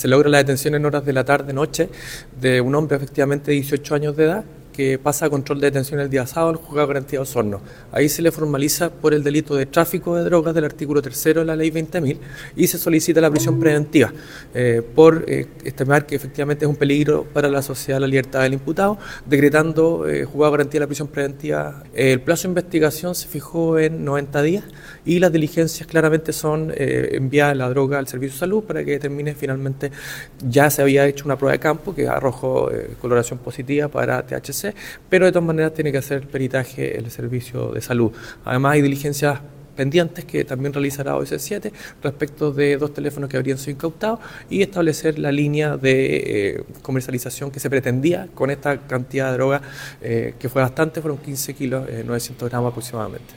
El fiscal adjunto de Osorno, Matías Montero, detalló que el detenido fue formalizado por el delito de tráfico de drogas, según Ley 20.000, y se decretó su prisión preventiva.